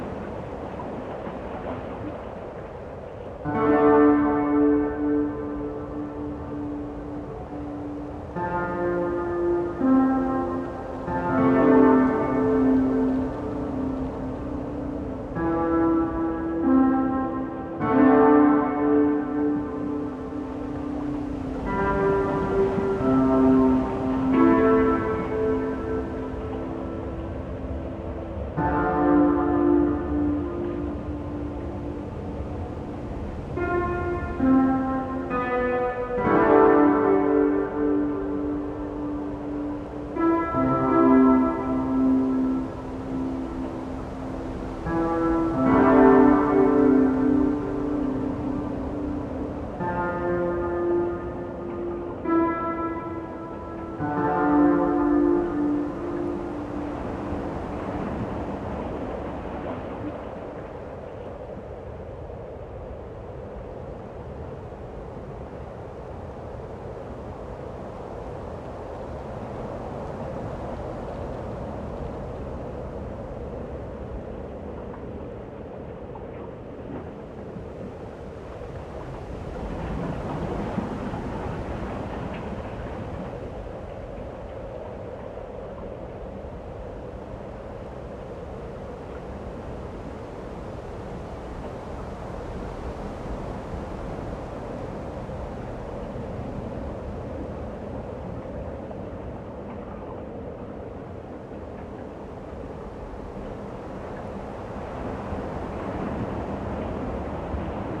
outside_ambience.wav